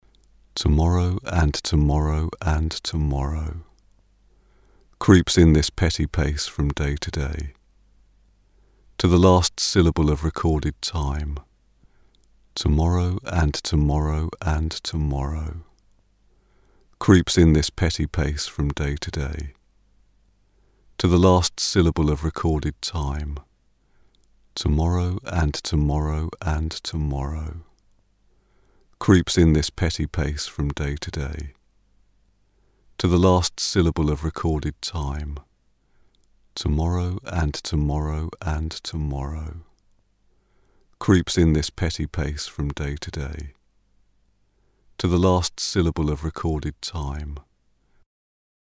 Чуть проапдейтил свою стерео-расширялку под Реактор.. Стало звучать более натурально, как мне кажется...
Вначале идёт моно, затем 50% влияния расширялки, затем 75% и далее 100%..